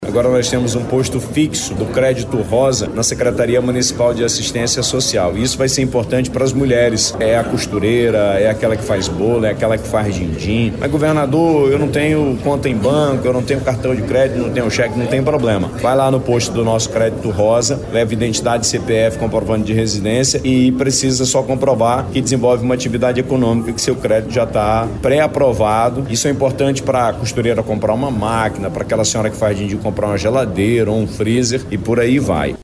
A medida busca dar mais estrutura para quem trabalha no campo, em uma região onde a produção agropecuária ainda enfrenta desafios de financiamento e regularização, explica o Governador do Amazonas, Wilson Lima.
SONORA-1-WILSON-LIMA.mp3